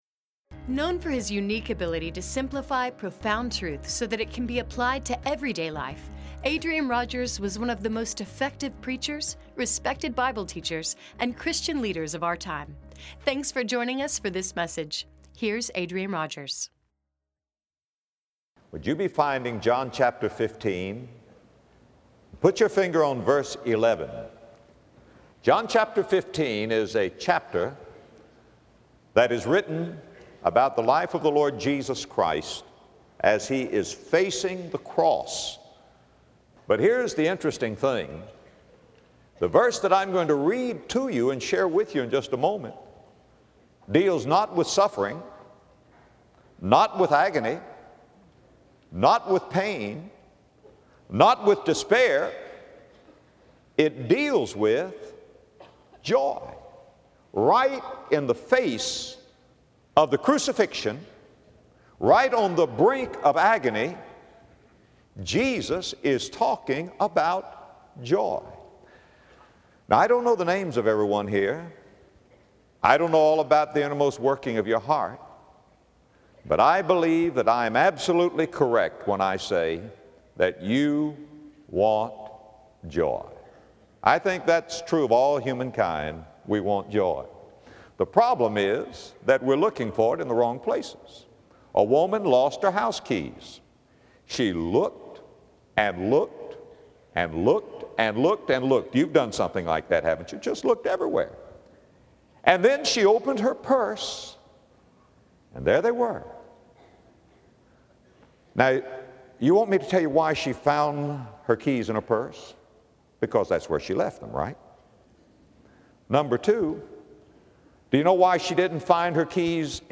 In this message, Adrian Rogers explains the joy described in John 15, and how to activate it in our lives.